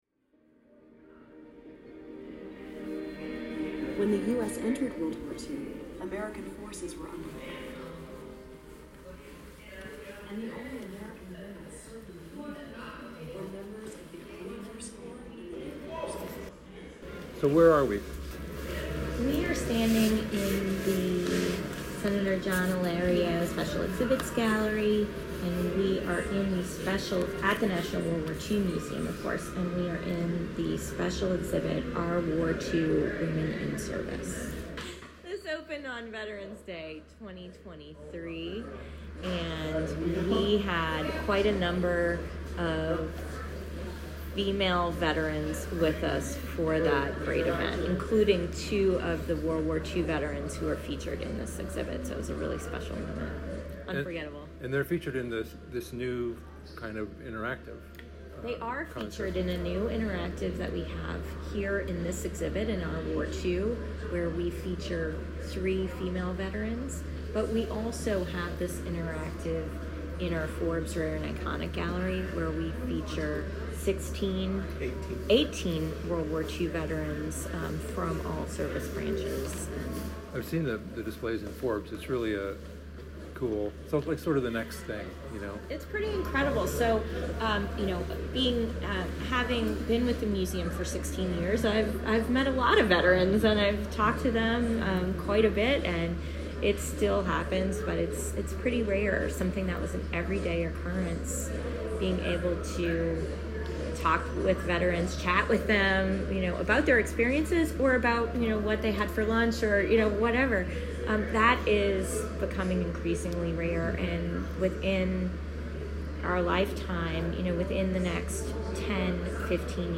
A recording of our walk-and-talk is here:
This conversation admittedly jumps around a little bit.
Also, apologies in advance for the audio quality.